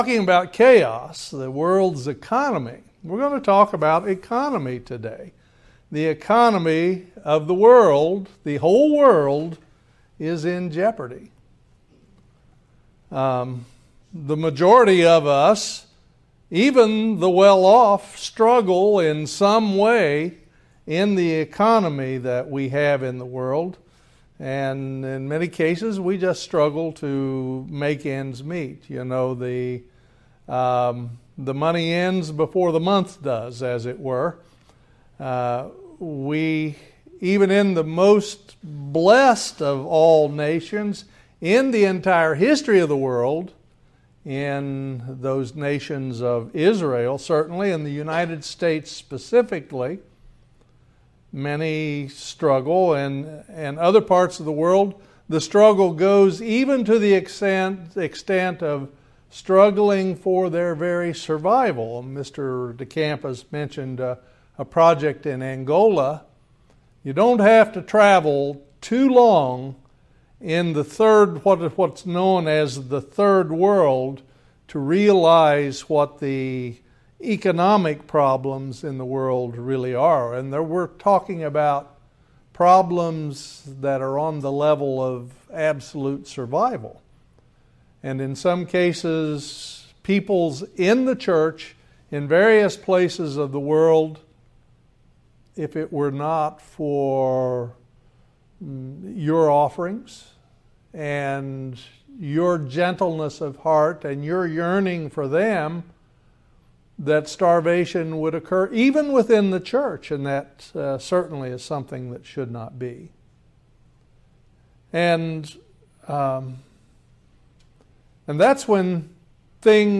Join us for this eye opening Video sermon about God's economy. Did you know God has an economic system thats never been tried?